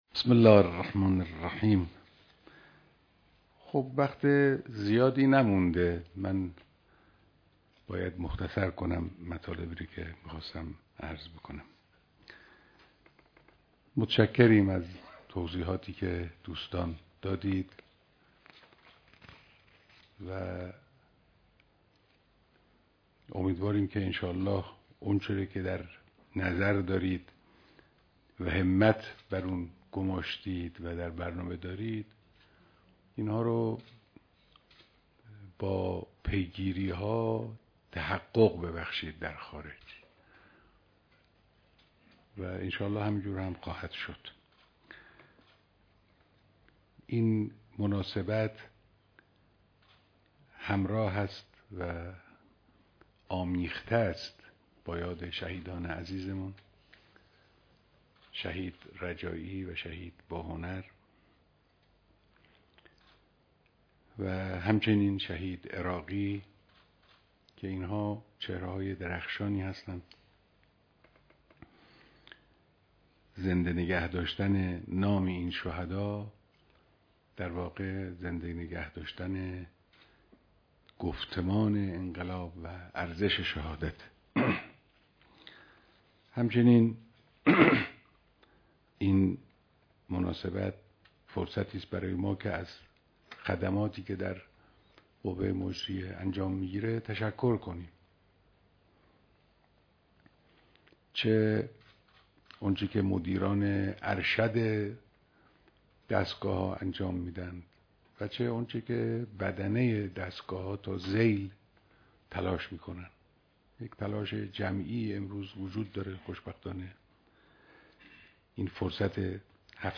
بیانات رهبر معظم انقلاب در ديدار اعضای هيأت دولت